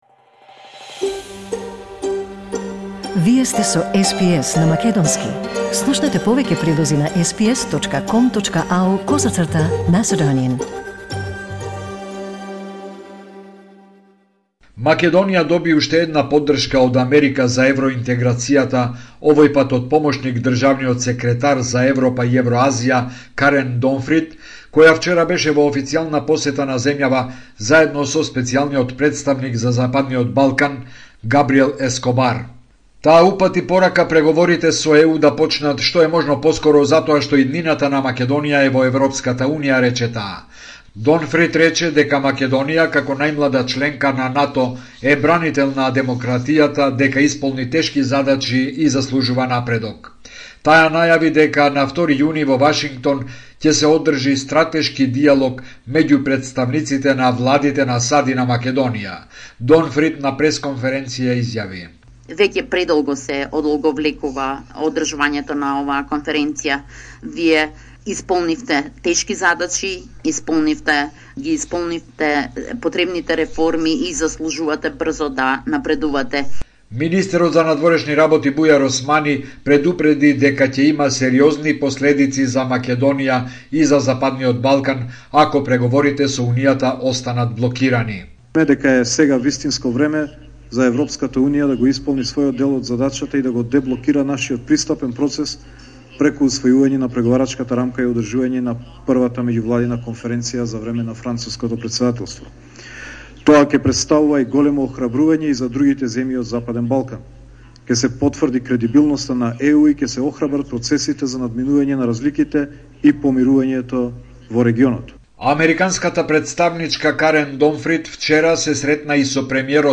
Homeland Report in Macedonian 29 April 2022